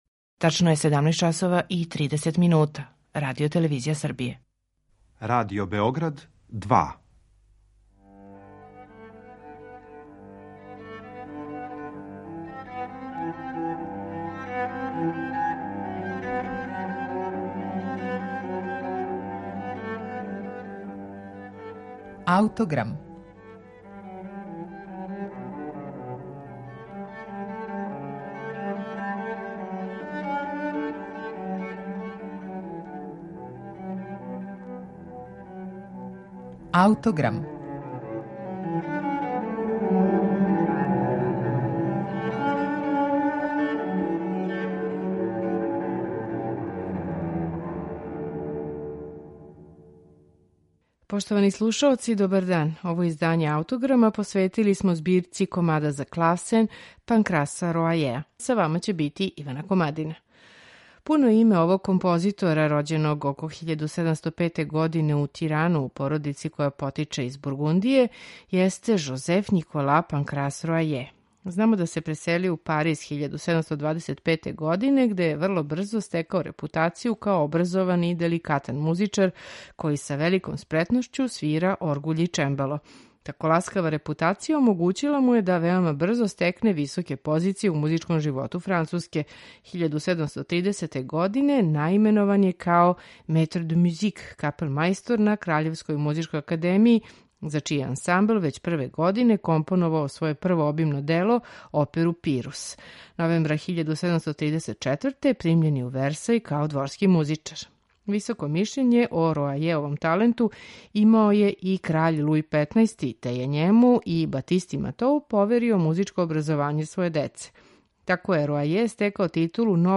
У данашњем скраћеном термину емисије Аутограм можете слушати комаде за чембало чији је аутор француски композитор из XVIII века Панкрас Роаје.
За данашњи Аутограм , посвећен овој Роајевој збирци, издвојили смо комаде које ћете слушати у интерпретацији Кристофа Русеа на чембалу израђеном 1751. године у радионици Хајнриха Хермша.